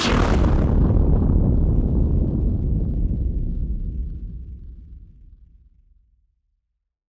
BF_SynthBomb_C-03.wav